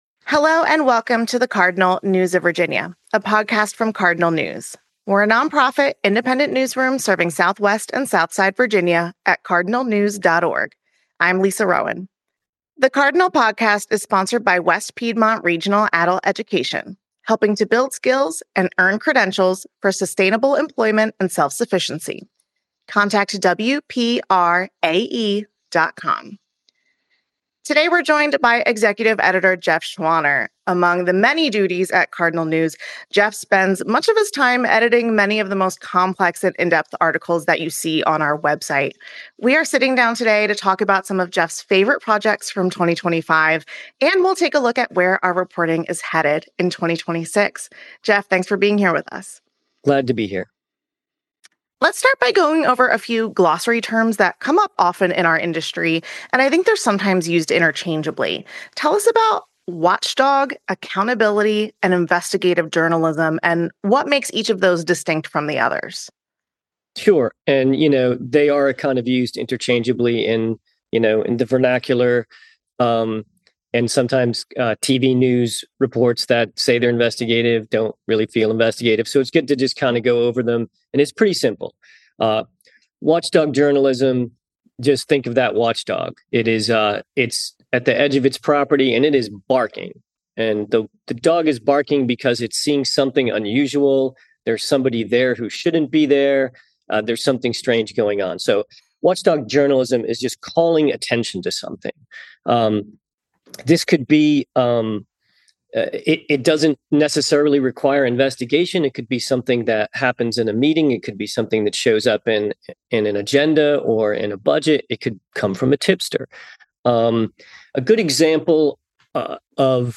From accountability reporting to investigative work, they discuss how Cardinal News covers power, policy, and people across the region. The conversation also turns forward, with a look at what the newsroom is watching and planning for 2026.